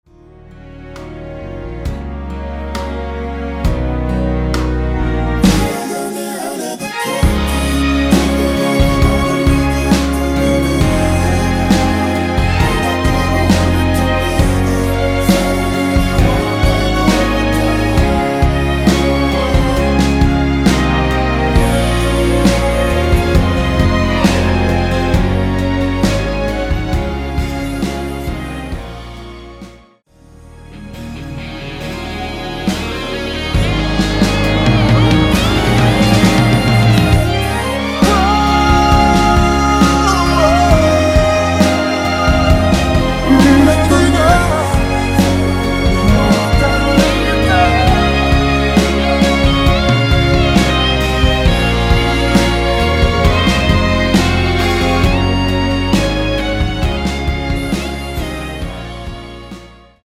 원키에서(-2)내린 코러스 포함된 MR입니다.
Db
앞부분30초, 뒷부분30초씩 편집해서 올려 드리고 있습니다.
중간에 음이 끈어지고 다시 나오는 이유는